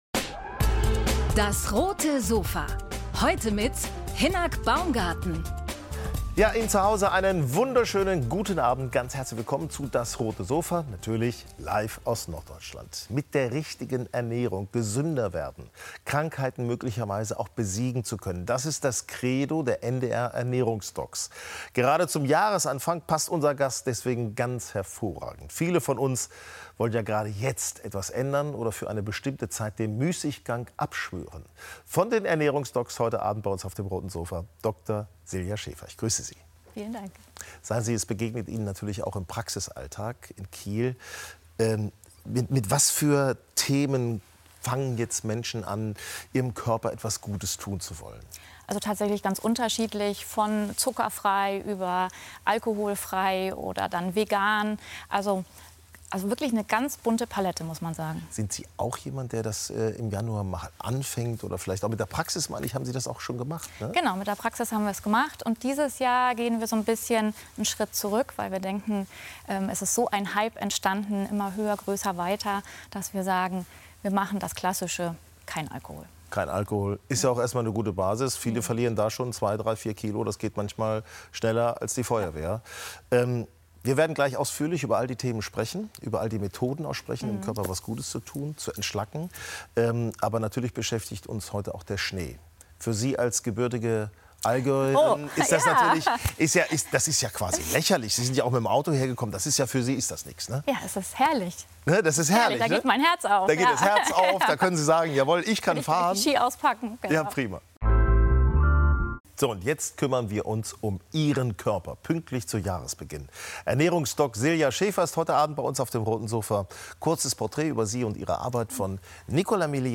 ~ DAS! - täglich ein Interview Podcast